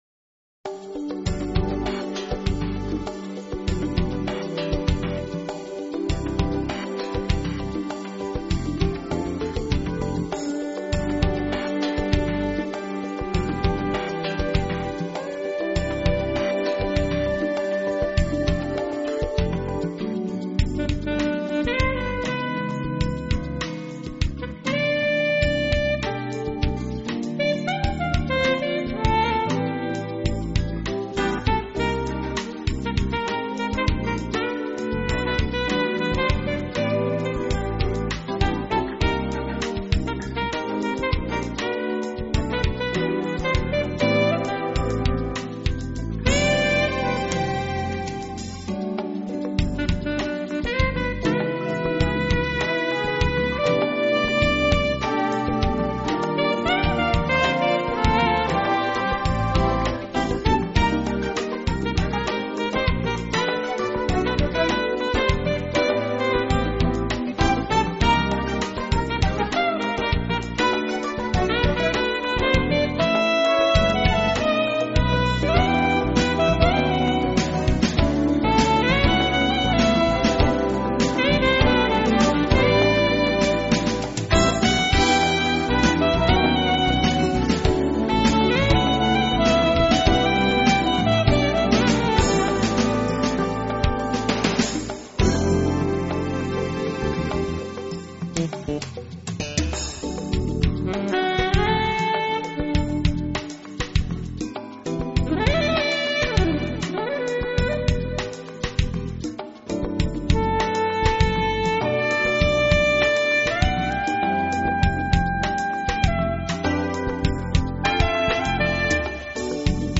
【纯音乐】